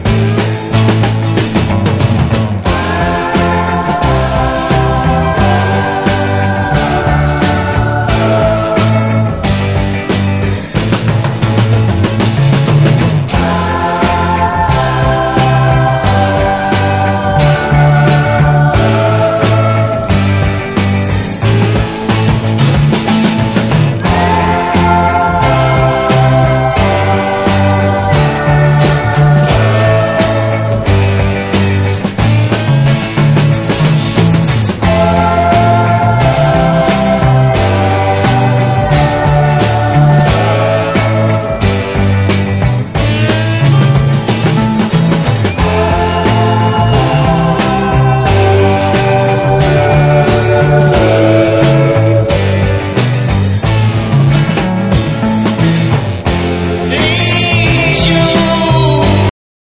東ドイツ産のファズ/60'Sビートなグルーヴィー男女デュオ！